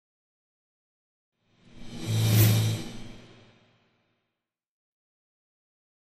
Drum And Thin Cymbal Hit Symphonic - Increasing